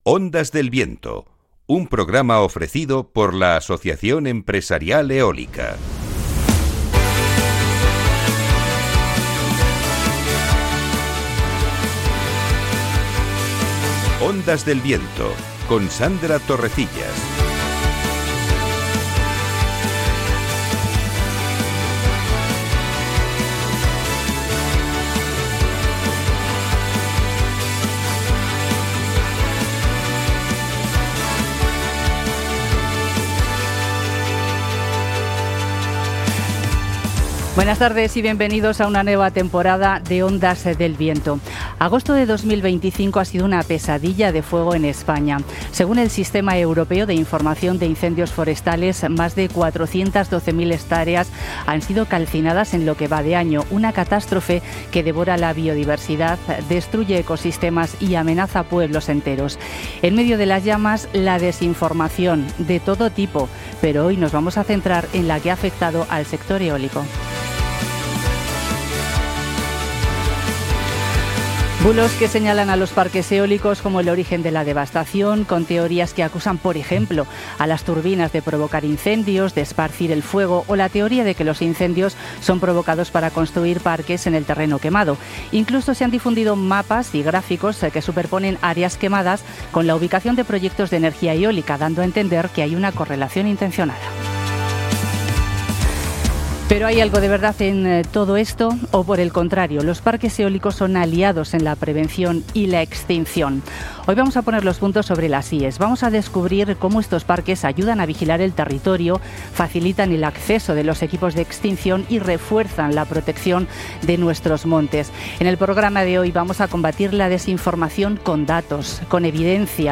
🎙Hoy 9 de septiembre hemos regresado tras el periodo vacacional con un nuevo episodio de Ondas del Viento, el programa radiofónico del sector eólico en la emisora Capital Radio.